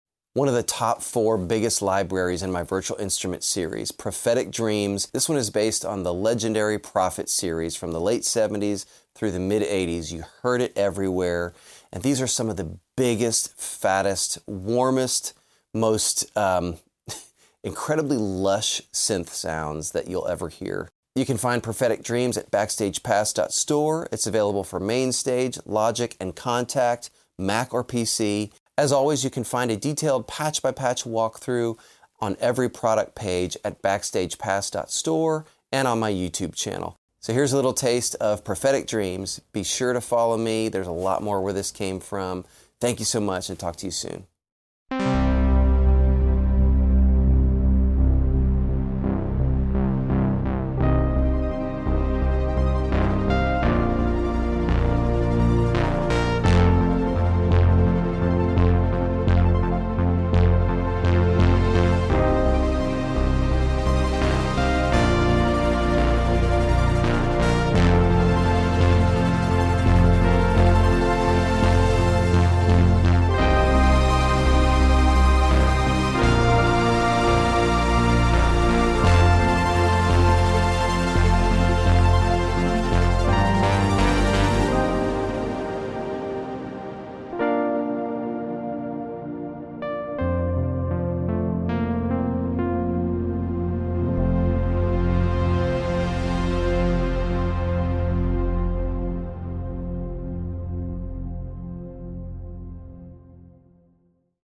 Want the unmistakable sound of a Prophet vintage synth?